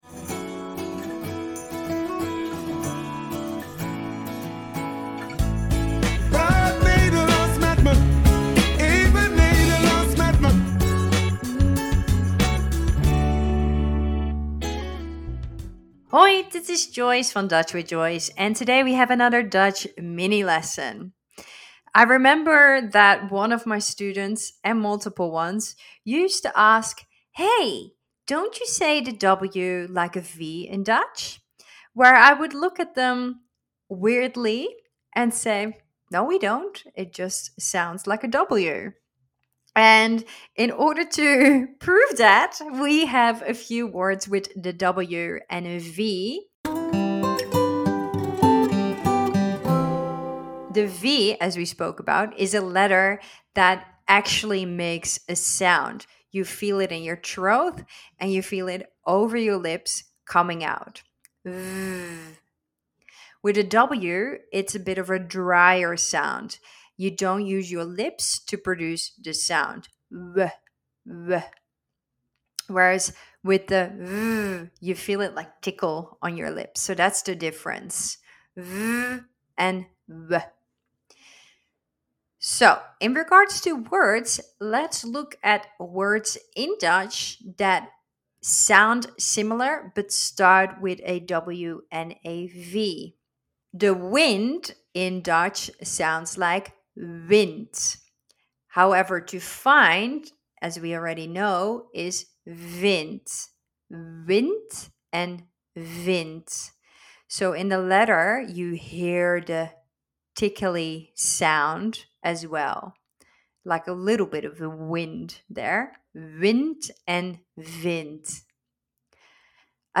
In deze podcastserie leer je wekelijks in een paar minuten enkele Nederlandse woordjes en uitspraken. De lessen zijn in het Engels en worden verzorgd door leraar Nederlands